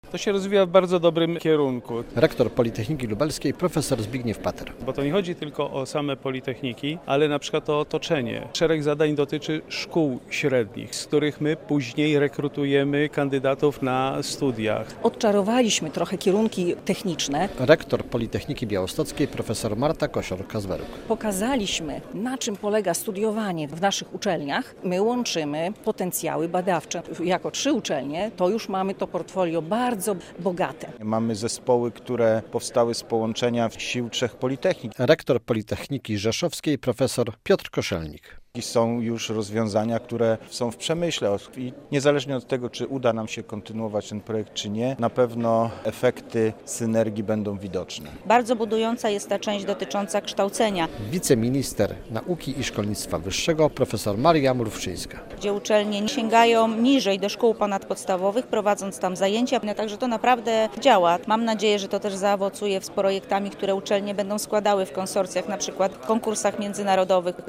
W środę (27.11) odbyła się w Białymstoku konferencja, podsumowująca dotychczasowe efekty współpracy: